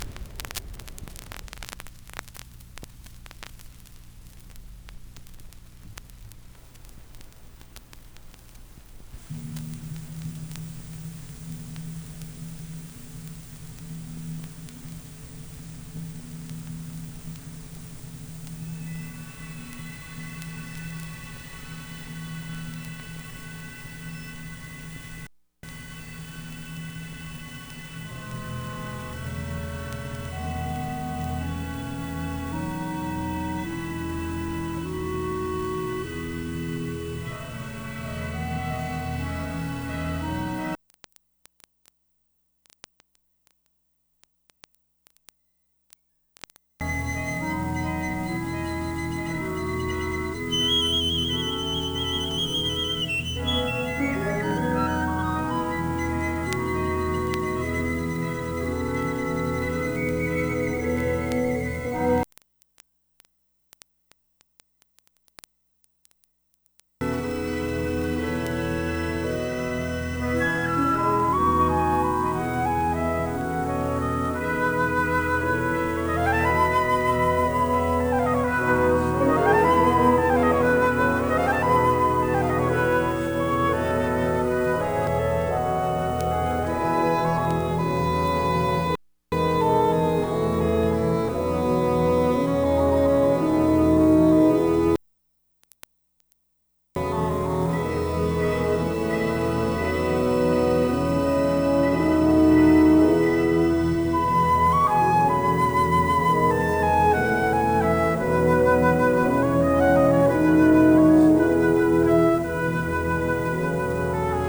Click on the link below to hear 1:54 of the Mercury “Grand Canyon Suite” in various states of “click repair” as follows:
26-32 SC-1 “in”, no click remover
32-41 click remover “in”
41-46 just the clicks
46-55 click remover “in”
56-1:02 click remover “out”